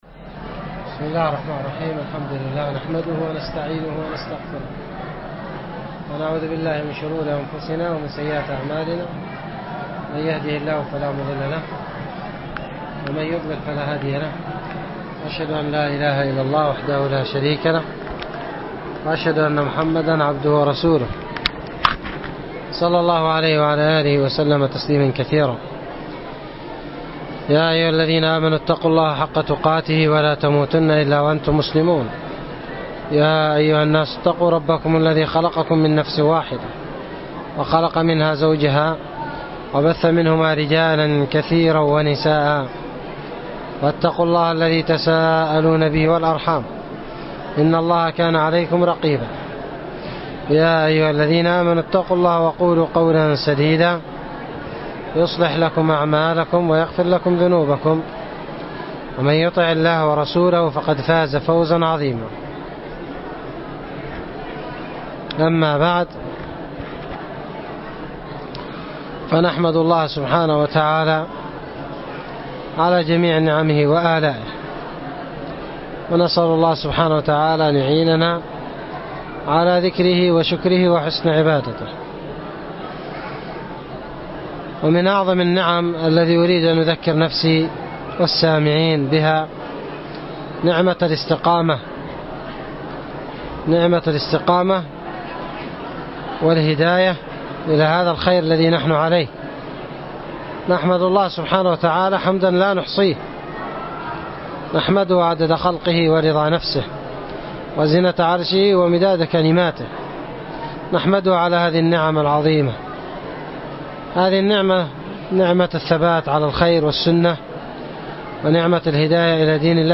محاضره